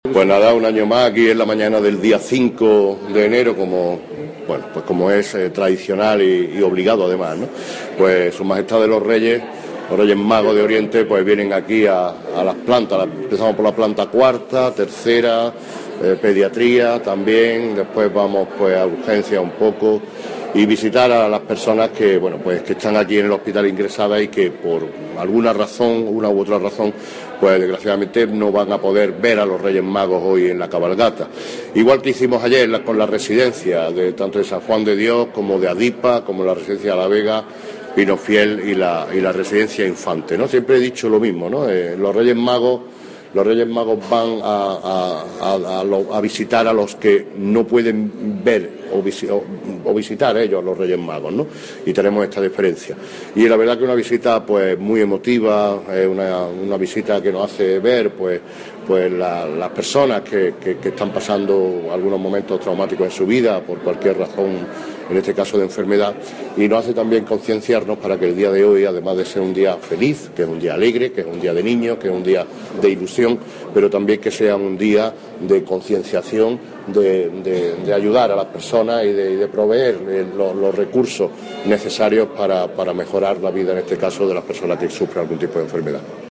Declaraciones del Alcalde de Antequera con motivo de la llegada de los Reyes Magos de Oriente
Generar Pdf martes 5 de enero de 2016 Declaraciones del Alcalde de Antequera con motivo de la llegada de los Reyes Magos de Oriente Generar Pdf Cortes de audio con las declaraciones que el alcalde de Antequera, Manolo Barón, ha realizado en la mañana de hoy martes 5 de enero en el transcurso de la visita de Sus Majestades los Reyes Magos de Oriente al Hospital Comarcal de Antequera. Estos audios se refieren a la visita concreta de los Reyes Magos, una petición que el Alcalde le hace a los mismos para la ciudad y una valoración sobre las cifras del desempleo respecto al último mes publicadas en el día de hoy.